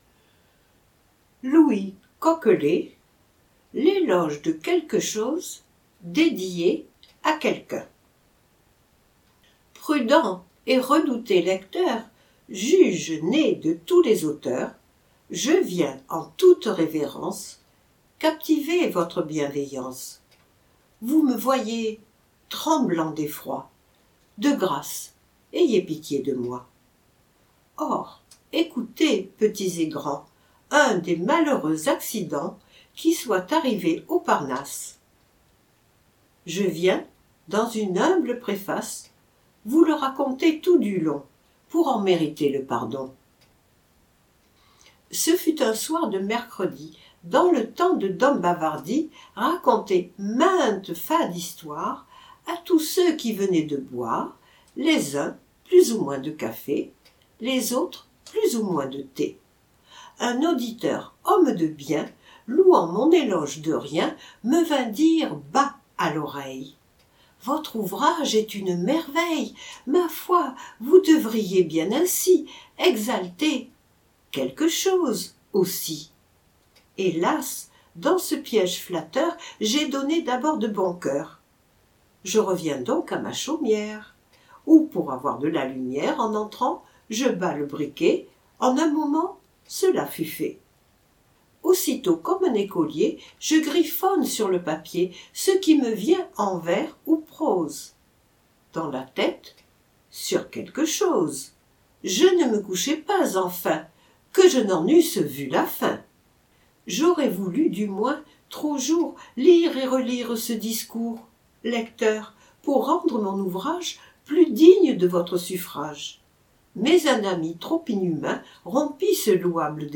Livres audio